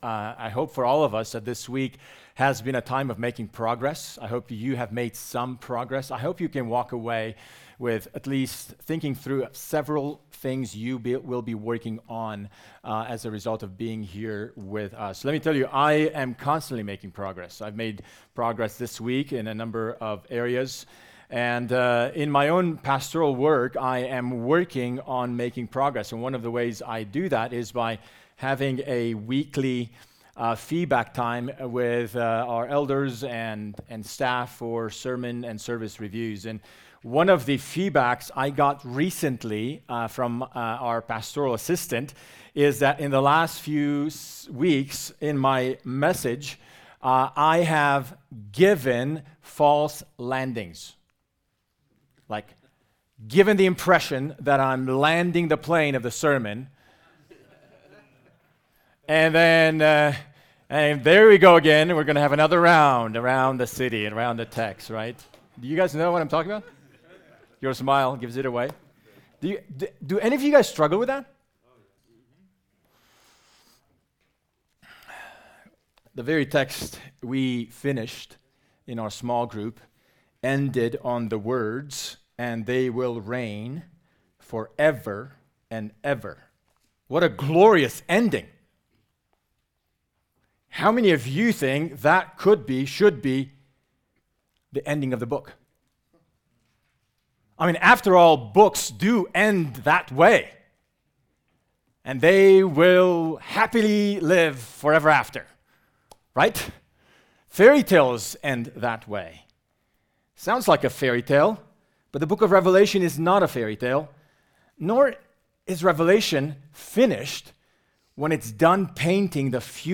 Fort Collins 2025 The spiritual health of the Church is dependent on the proclamation of Scriptures through the power of the Holy Spirit by preachers and teachers who really believe the Bible to be the words of God.